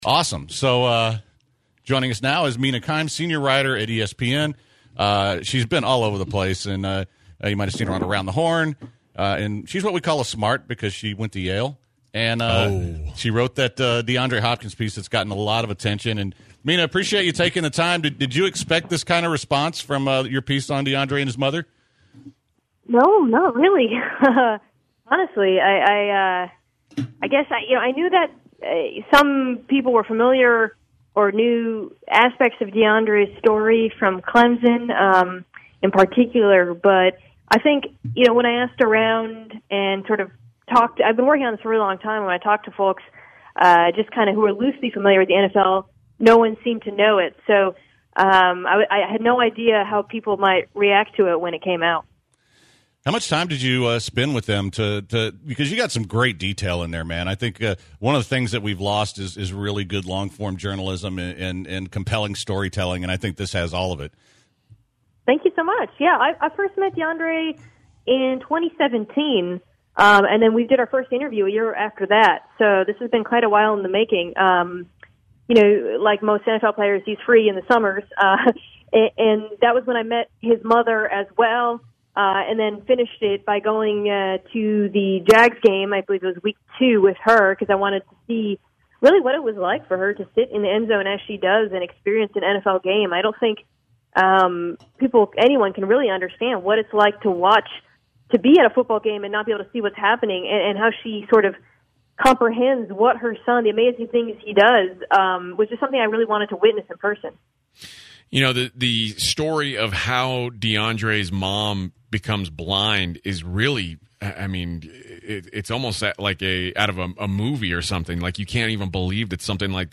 10/17/2019 Interview with Mina Kimes